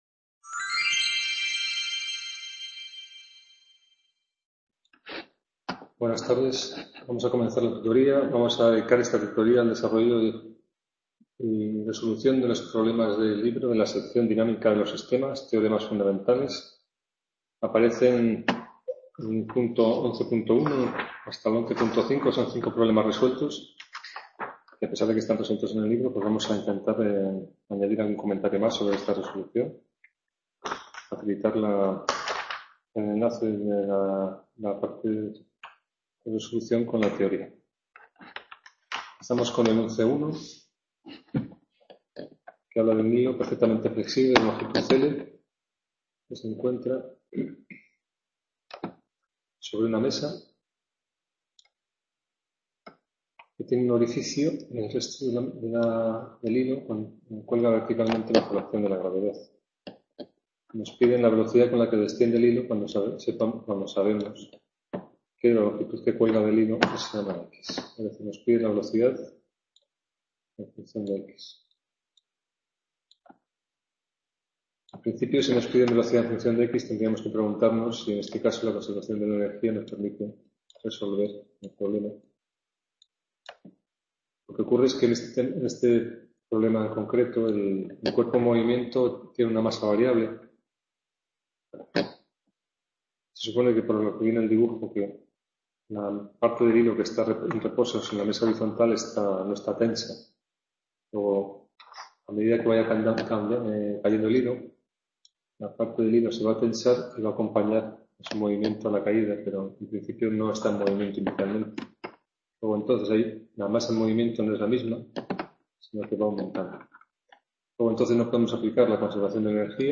Tutoria Mecanica II - 9 marzo 2016 - Problemas de… | Repositorio Digital